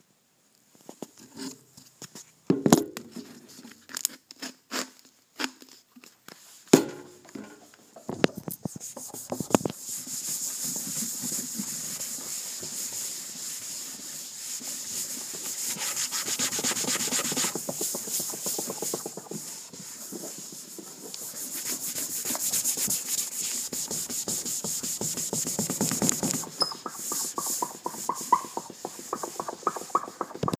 Identify the location of the clip – Design Studio
This is heard with a tap open of the lid and then short jerks and pulls of the wipe until it comes free from the container.
You can hear a swooshing noise as she cleans the desk and then a squeaking and more aggressive pattern as she works harder to get spots off of her desk.
Field-Recording-11.mp3